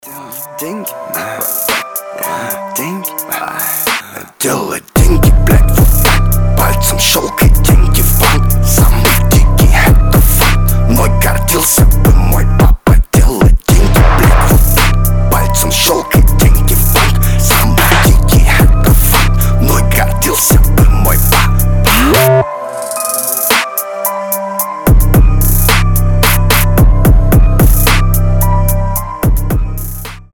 • Качество: 320, Stereo
мощные басы
качающие
мрачные
Cloud Rap
Alternative Rap
устрашающие